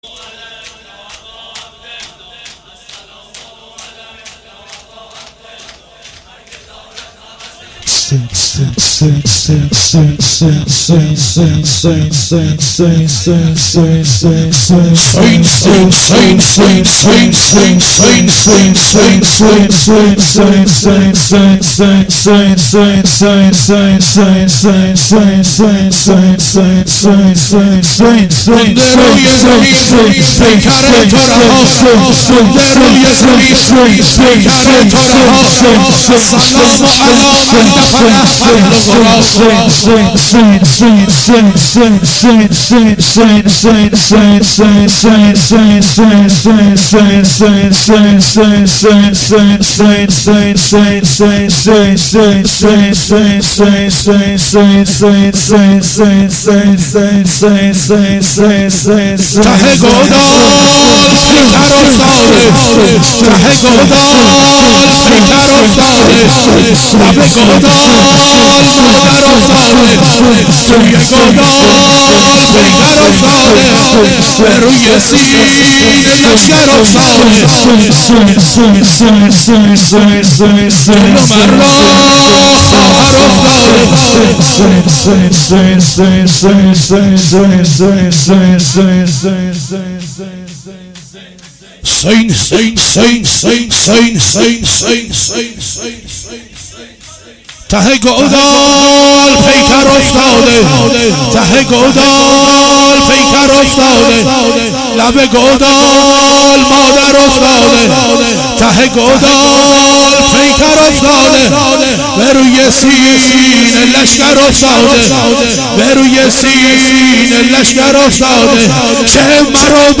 شور شب هشتم محرم 96